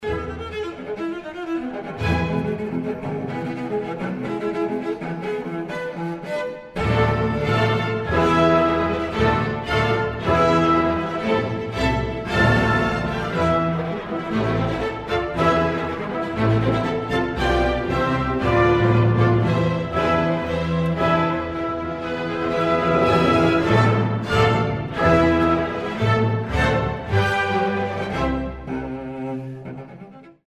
R. Schumann Cello Concerto Heart-on-sleeve music, one gorgeous melody after another
cello. The New Jersey Symphony backs him up with Schumann’s richest Romantic harmonies.